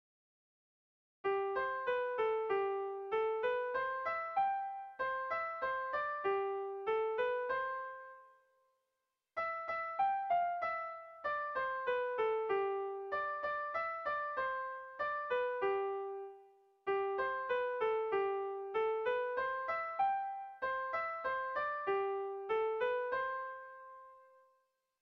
Dantzakoa
Seiko handia (hg) / Hiru puntuko handia (ip)
ABA